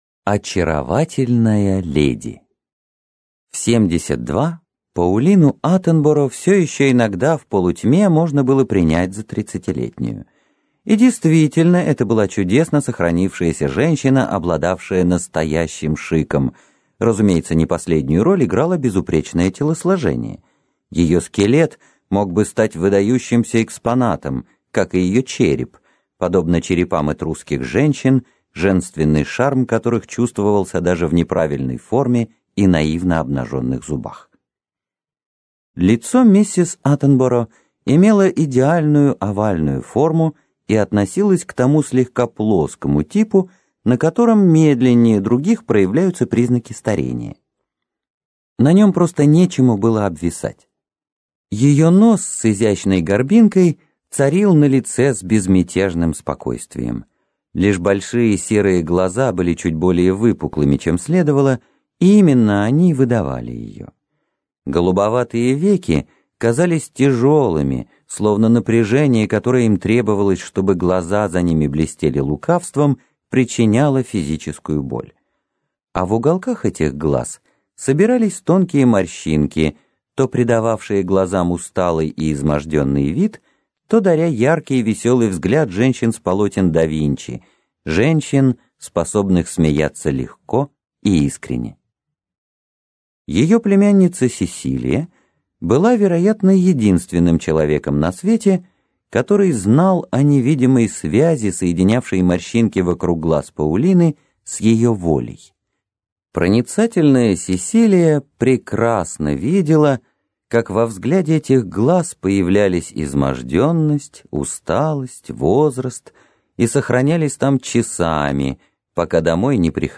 Аудиокнига Очаровательная леди. Рассказы / Lawrence, David Herbert.